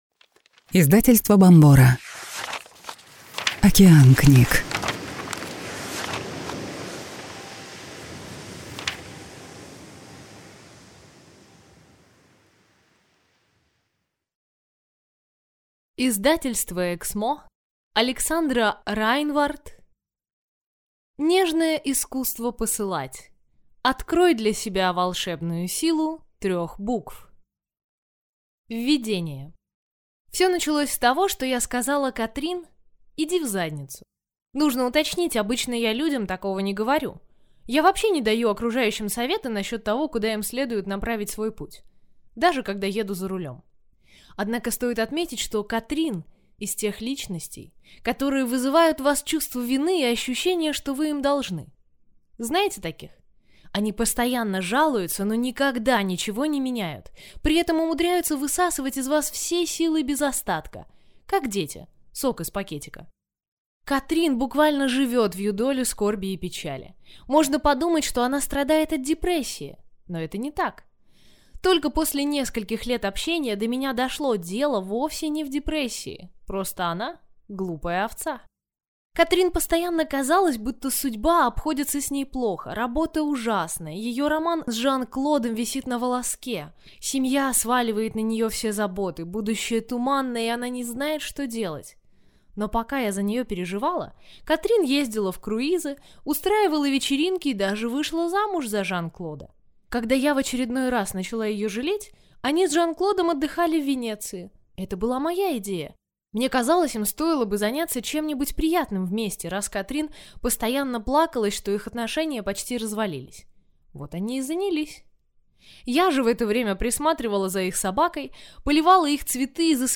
Аудиокнига Нежное искусство посылать. Открой для себя волшебную силу трех букв | Библиотека аудиокниг